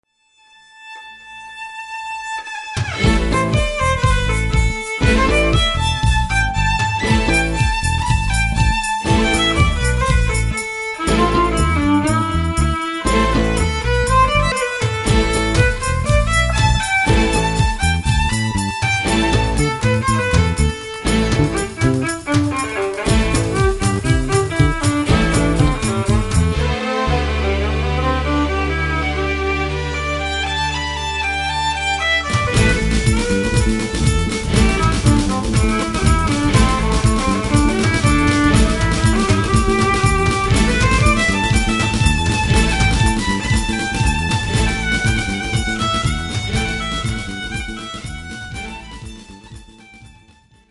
fiddle
bass
drums/perc.
guitar&percussion